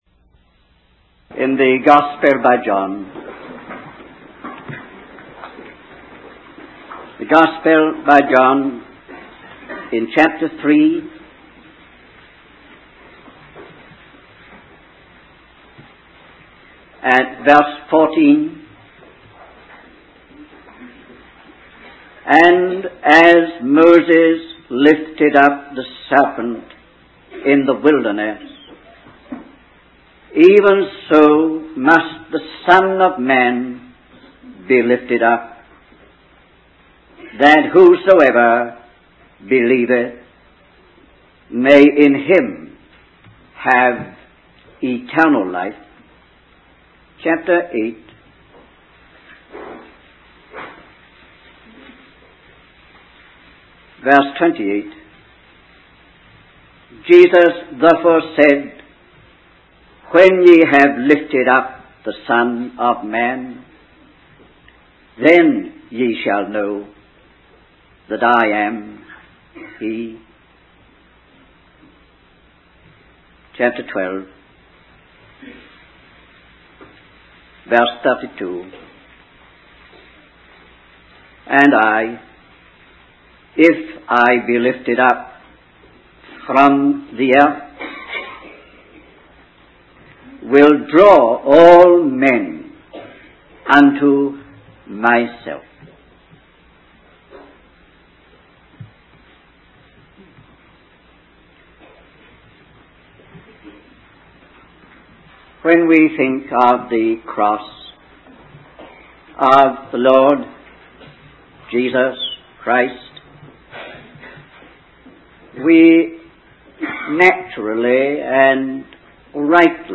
In this sermon, the speaker emphasizes the immense power of evil forces in the world and how they laugh at human efforts to undo their work. He highlights the futility of trying to repair the damage caused by sin and the downfall of leaders and nations before this force. However, the speaker also points out that there is one thing that can overcome this power, and that is the cross of the Lord Jesus.